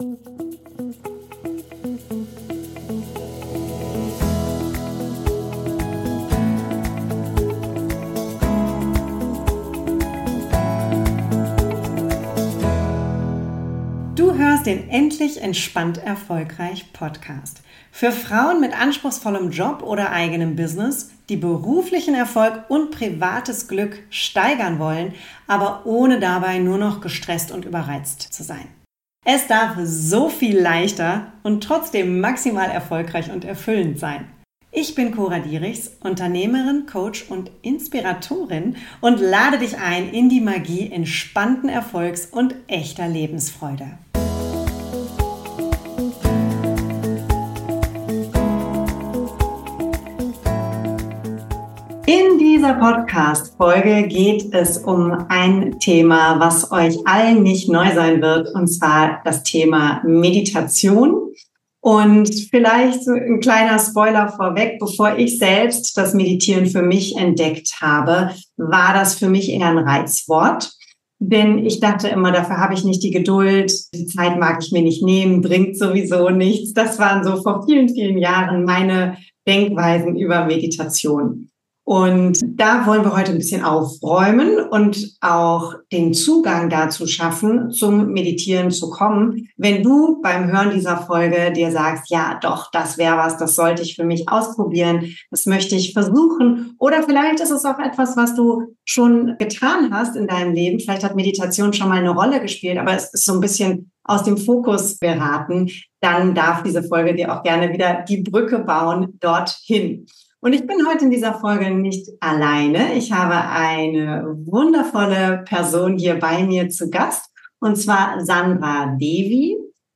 #042 Innere Ruhe to go: Meditation alltagsnah für Vielbeschäftigte - Interview